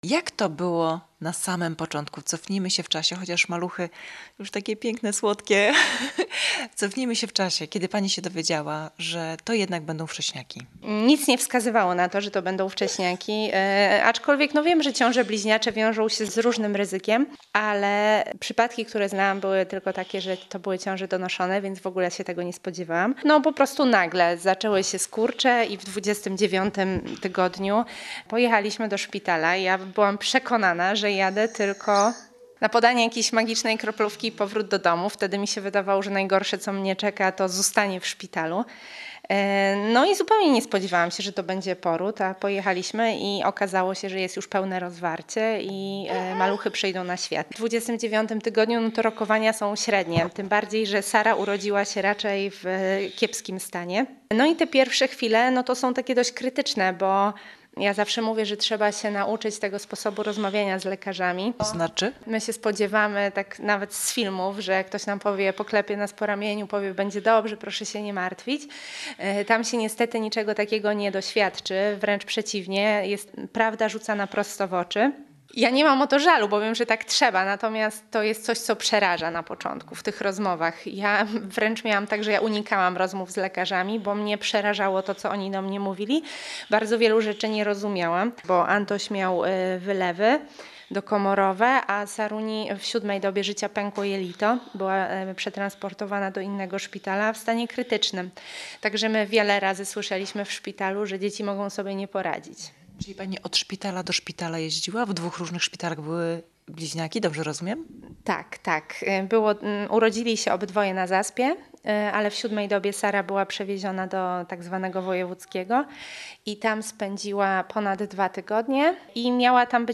Audycja „Sposób na rodzinę” czasami nagrywana jest w studiu, a czasami w gościnnie w terenie.
Wielodzietnej mamie łatwiej jest się spotkać w rodzinnym domu niż w rozgłośni.